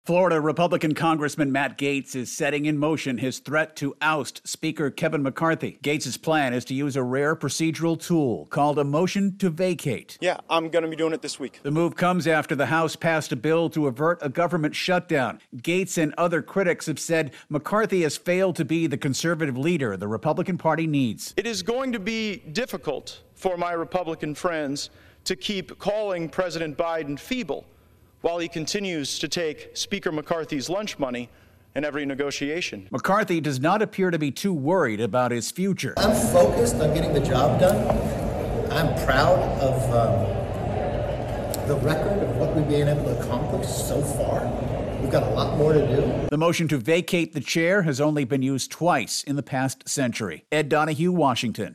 Explainer